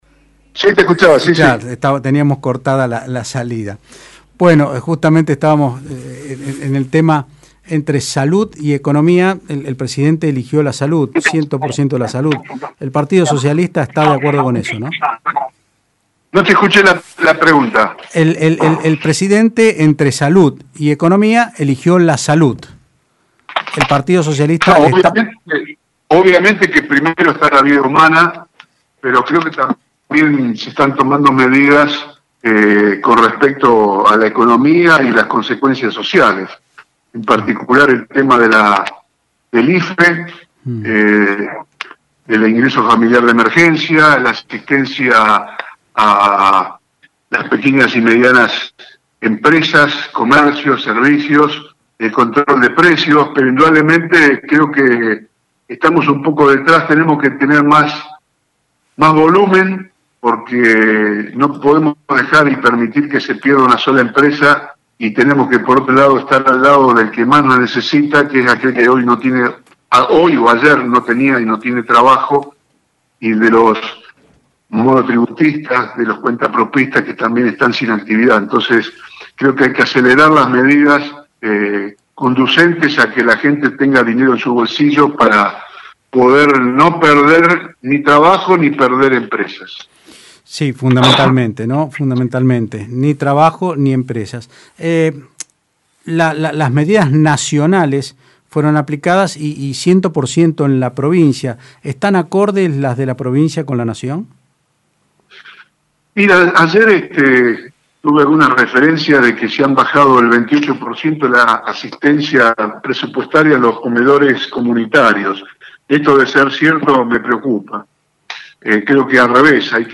El Presidente de PS Antonio Bonfatti dijo en Otros Ámbitos (Del Plata Rosario 93.5) que el gobierno debería aumentar la ayuda, inyectar dinero y salvar a los argentinos.